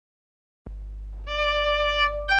The original recording was made on an Akai 4000DS MkII stereo ¼″ tape deck using Agfa-Gevaert PEM368 tape.
If we zoom-in on the start of the track, we see that there is a brief section of just the noise before the music starts. This is principally 50 Hz mains hum, but there is tape hiss superimposed. These also contaminate the music that begins 1.25 seconds into the recording.
You will probably have to increase the volume to hear the noise, but don't forget to turn it down again particularly if you are wearing earphones!
DM_start_noise.mp3